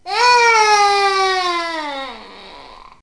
1baby1.mp3